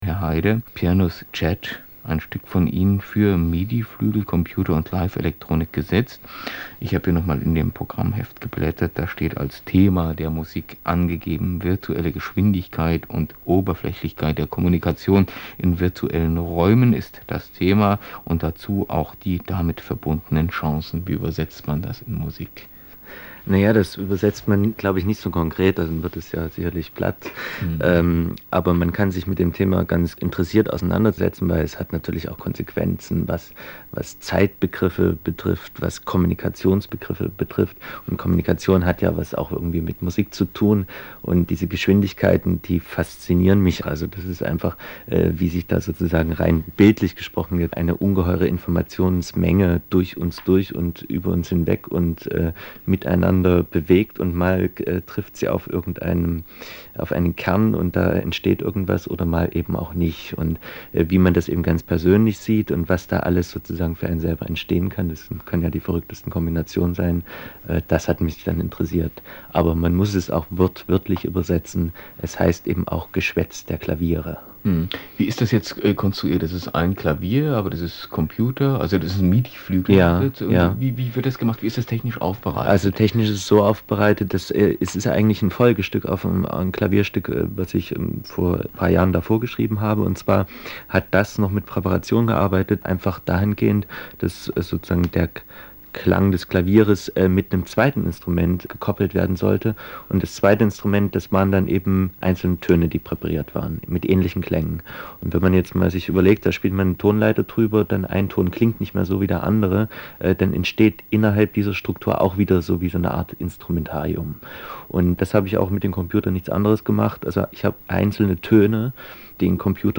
Interview im Deutschlandradio zu Piano(s)-Chat